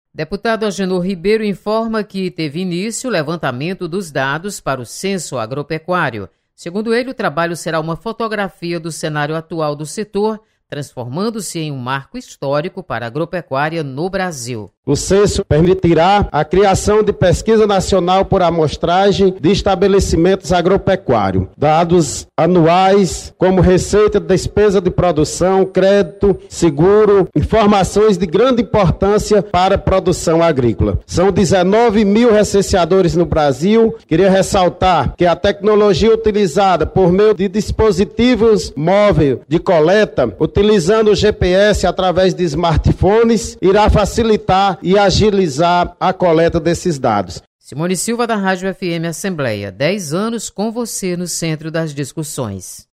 Deputado chama atenção para novo censo. Repórter